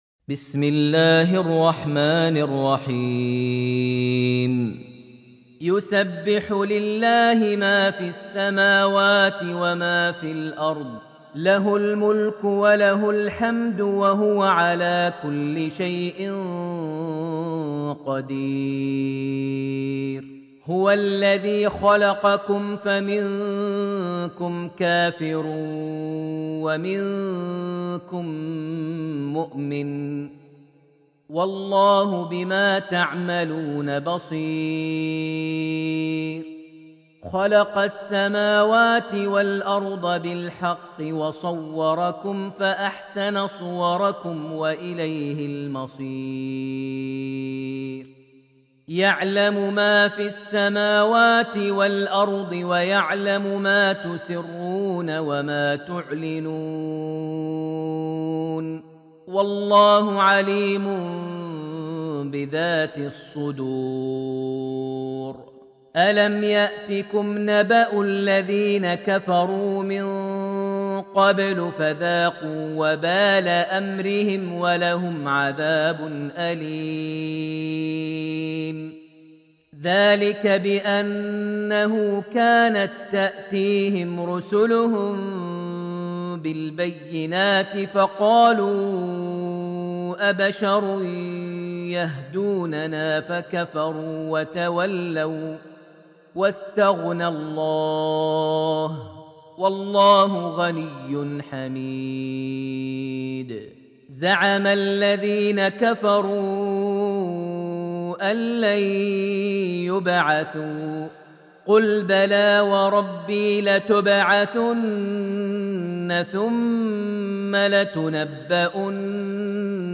Surah Al-Taghabun > Almushaf > Mushaf - Maher Almuaiqly Recitations